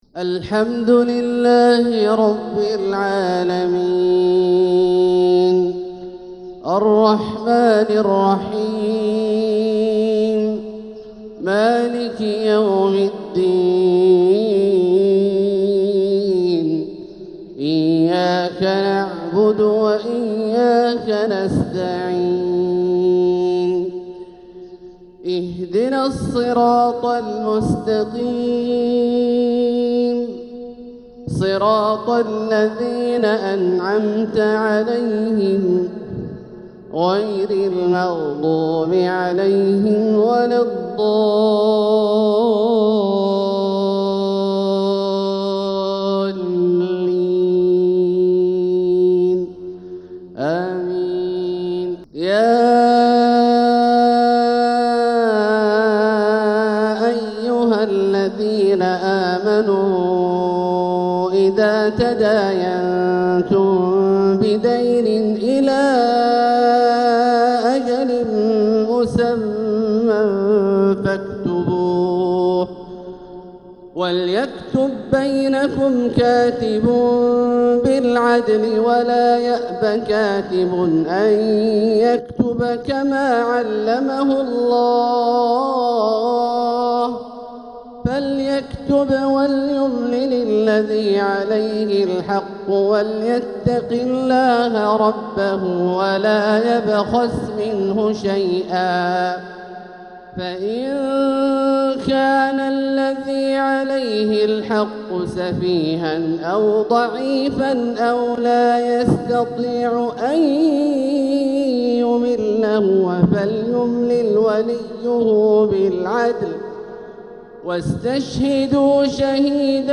ترتيل يفوق الوصف  لآخر سورة البقرة فجر اليوم ٧ جمادى الأولى ١٤٤٦هـ > ١٤٤٦ هـ > الفروض - تلاوات عبدالله الجهني